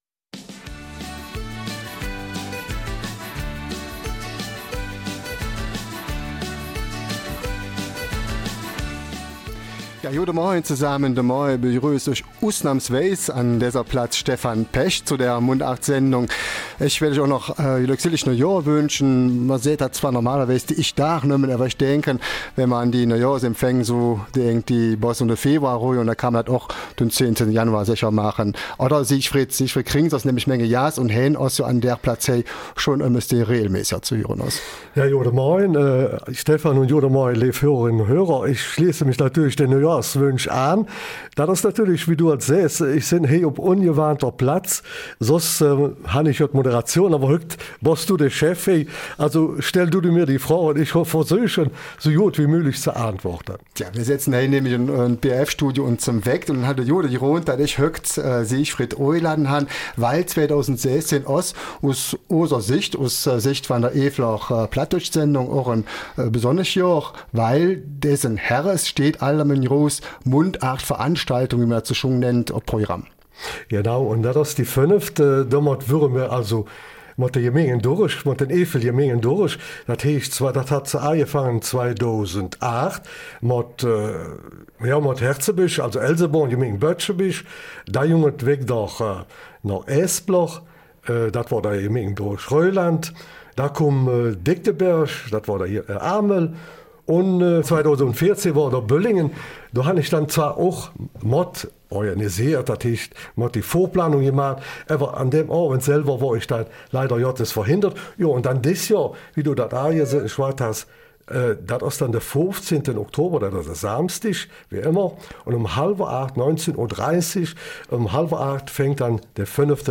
Eifeler Mundart: Plattdeutscher Abend im Triangel St. Vith
Ob in Form von Gedichten, Reden, Sketchen, Liedern und Tanz, ob als Einzelvortragender oder in Gruppen und Vereinen: Alle Beiträge müssen in dem jeweiligen Dialekt des Ortes vorgetragen werden.